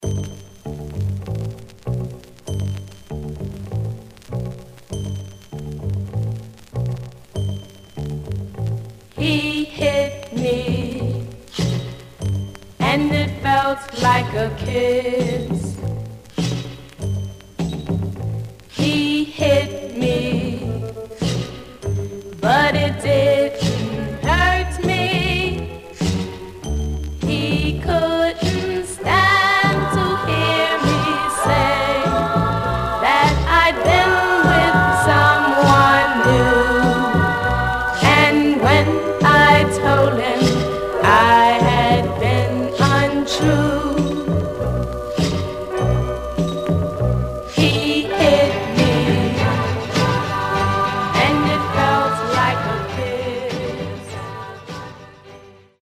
Some surface noise/wear
Mono
White Teen Girl Groups